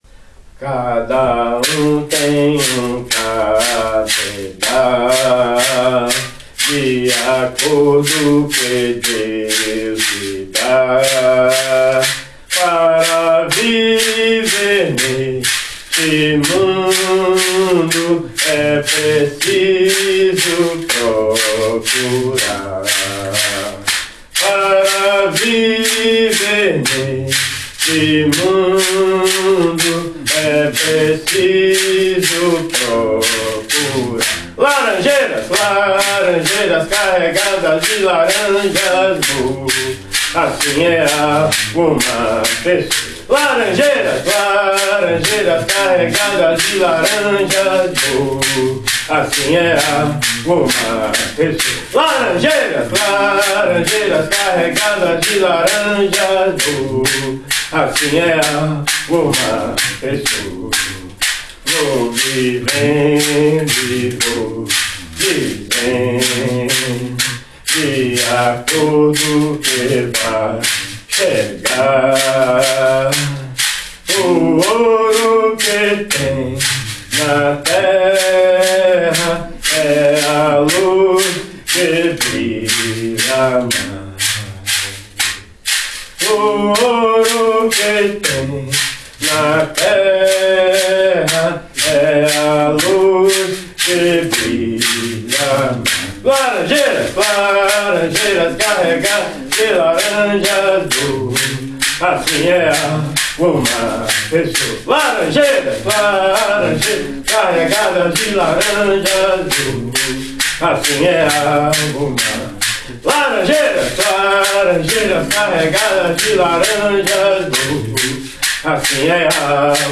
Hymne 60 Laranjeira von Hymnarium O Cruzeiro